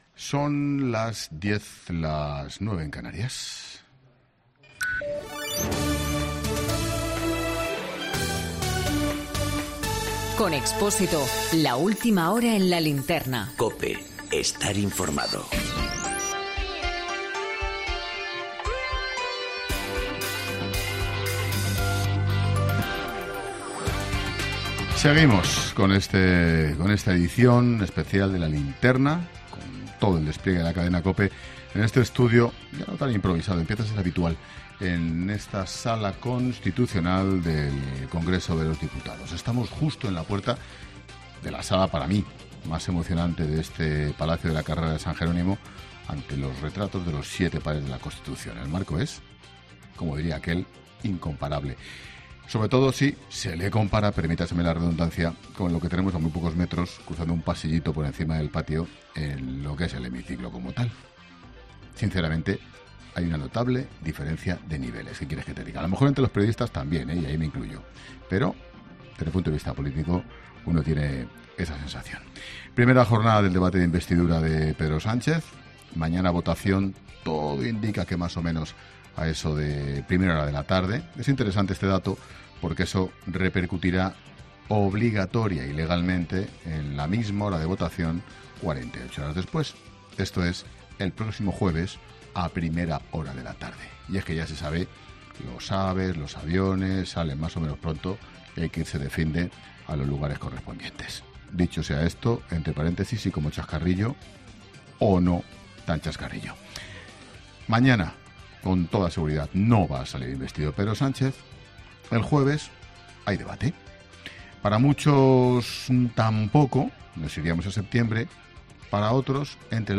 Boletín de noticias de COPE del 22 de julio de 2019 a las 22.00 horas